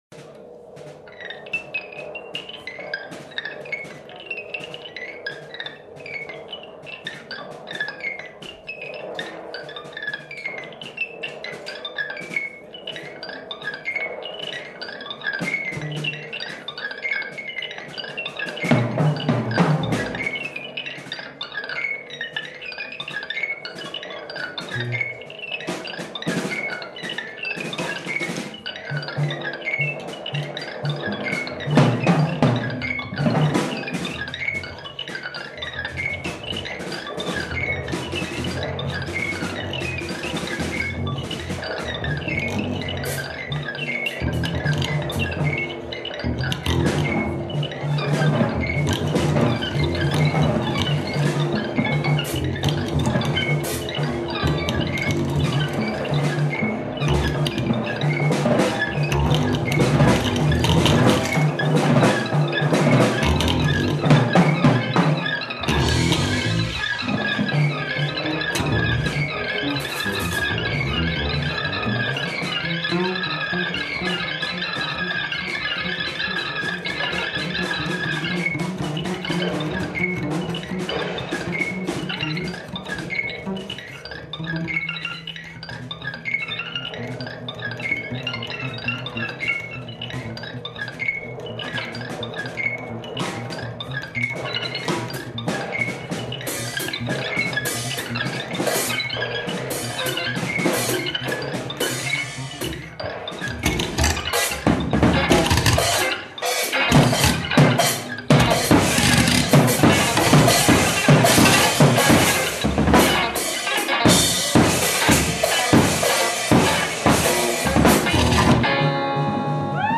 double bass, electronics
electric guitar, hardware
drums, percussion The continuous improvising